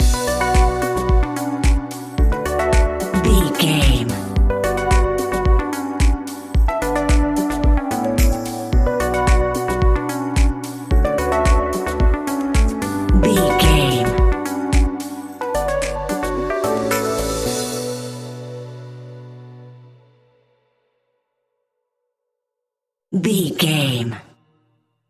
Aeolian/Minor
groovy
dreamy
smooth
futuristic
drum machine
synthesiser
house
electro
synth leads
synth bass